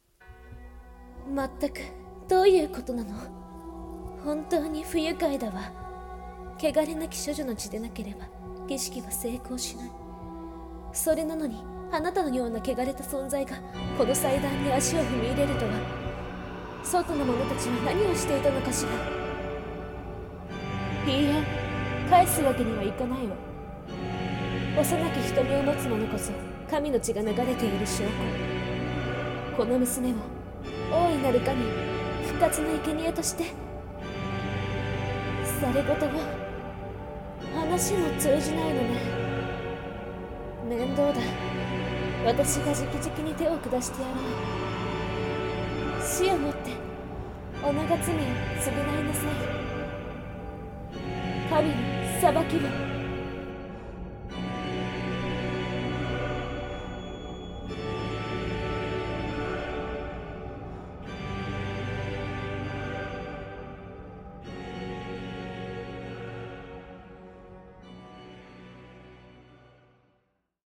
【声劇】神の裁きを